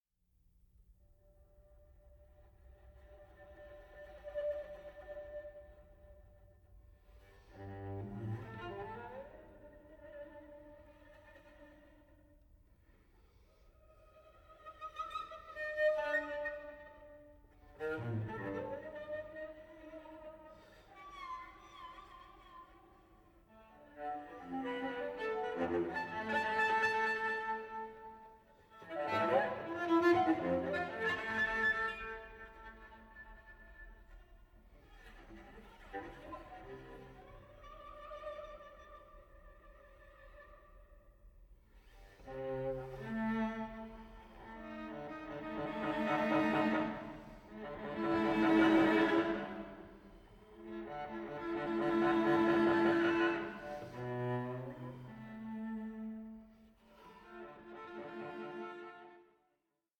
infectious energy and kinesthetic rhythms